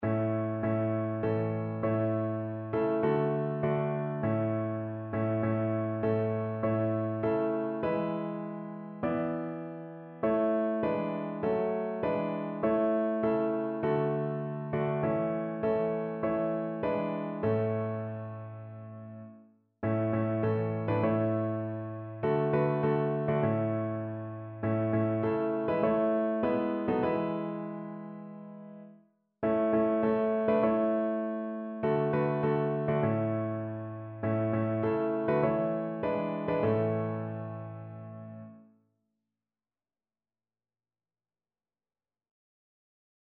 Notensatz (4 Stimmen gemischt)